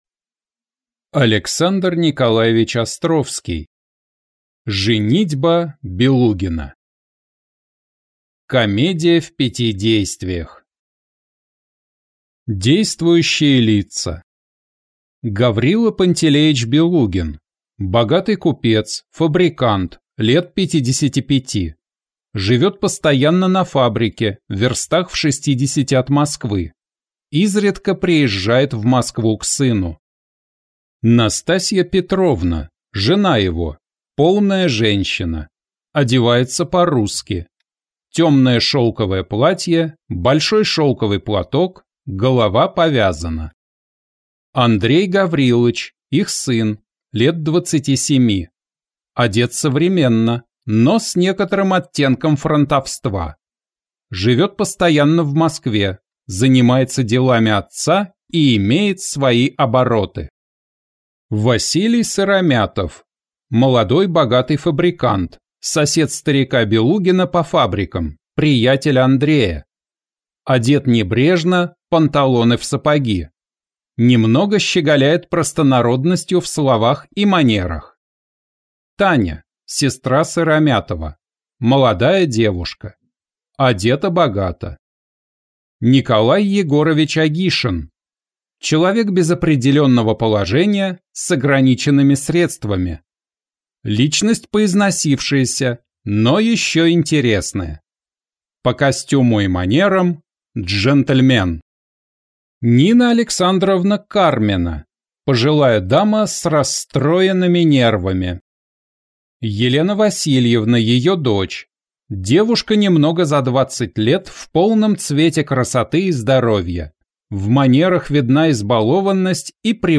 Аудиокнига Женитьба Белугина | Библиотека аудиокниг